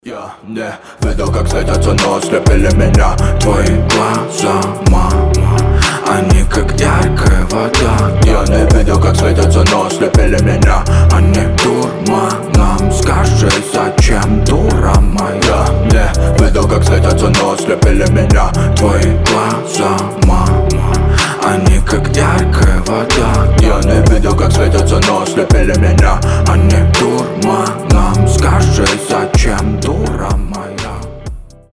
• Качество: 320, Stereo
лирика
атмосферные